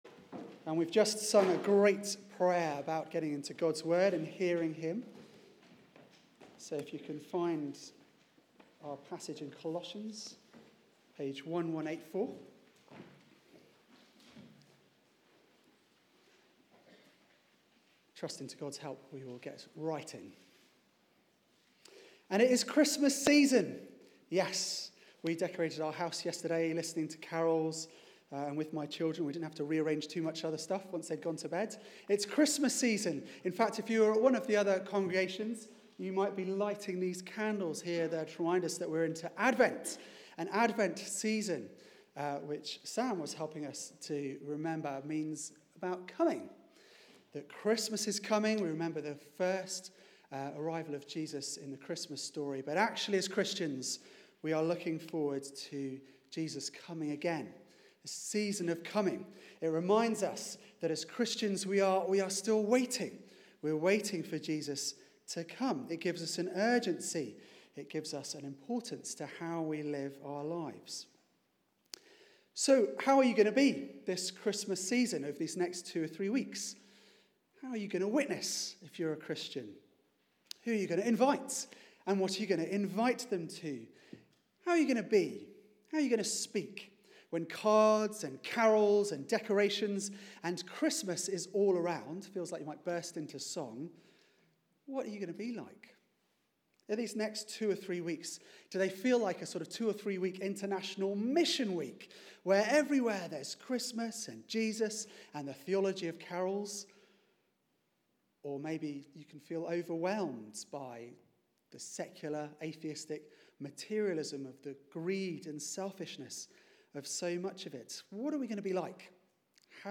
Media for 6:30pm Service on Sun 04th Dec 2016 18:30 Speaker
Passage: Colossians 4:2-18 Series: Rooted in Christ Theme: Making Christ known Sermon